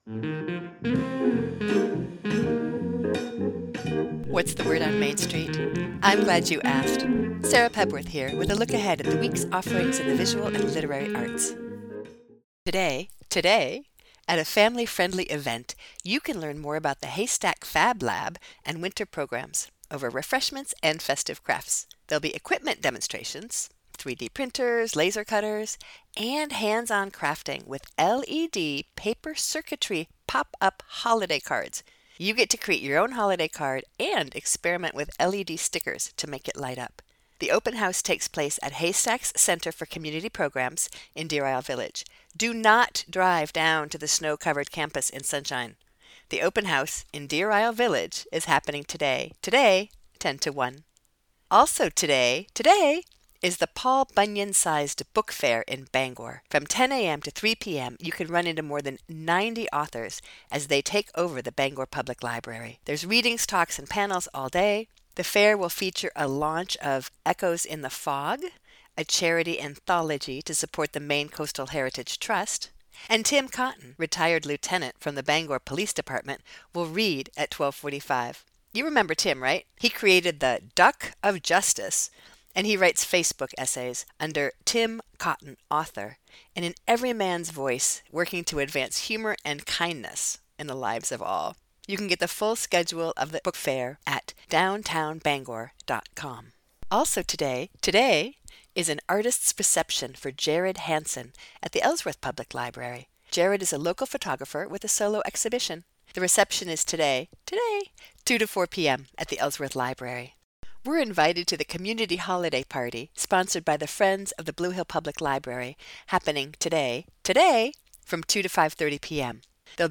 Infinite Blues is a cut from his recently released neon night, an excursion into an ambient/electronic musical world built around rhythmic bass ostinatos, clouds of processed looping electronic atmospheres, and melody. By turns both subtle and unapologetically noisy, the songs are a collection of luminous constellations, roved between by a band of texturally minded instrumental improvisers.